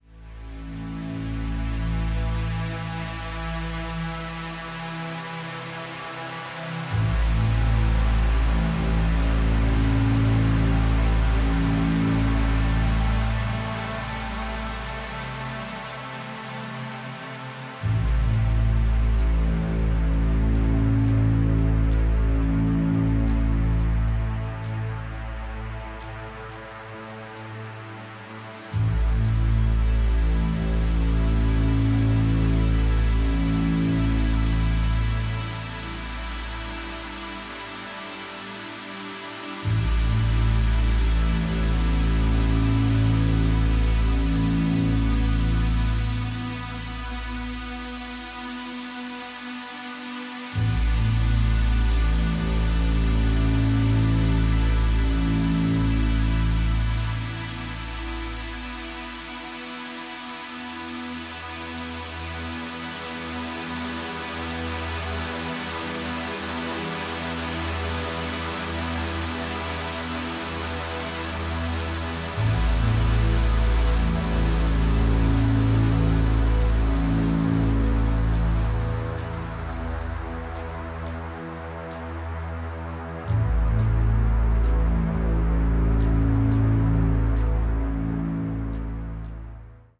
reflective ambient excursions